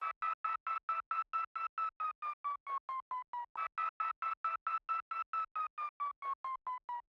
SWEEP FX.wav